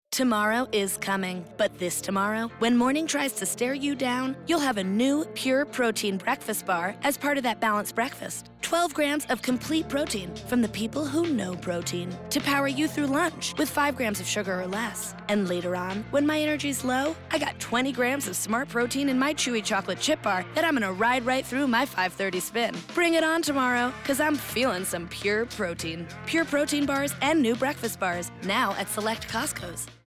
Costco Pure Protein Bar Radio Spot - Voice Over (May 2018)